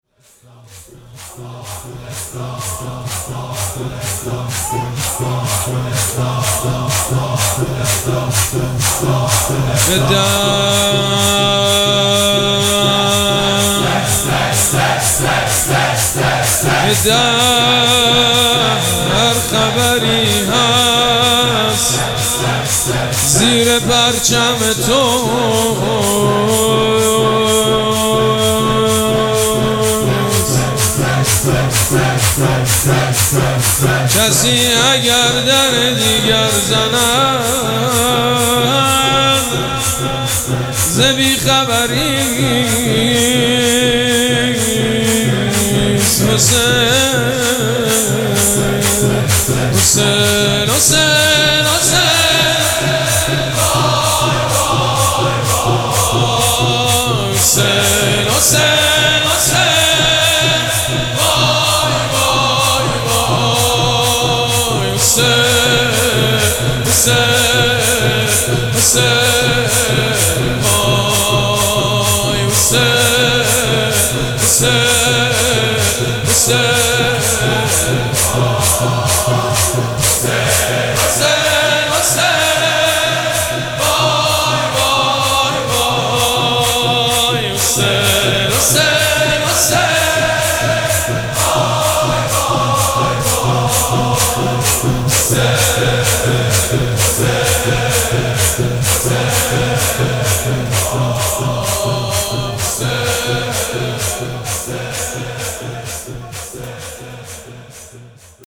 مراسم عزاداری شب سوم محرم الحرام ۱۴۴۷
شور
مداح
حاج سید مجید بنی فاطمه